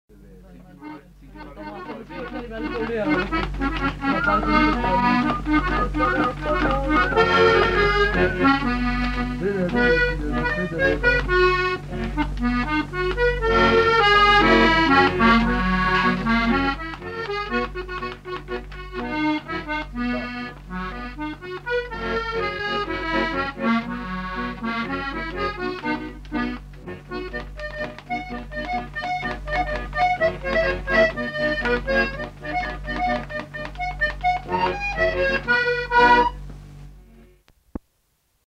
Lieu : Polastron
Genre : morceau instrumental
Instrument de musique : accordéon diatonique
Danse : quadrille
Ecouter-voir : archives sonores en ligne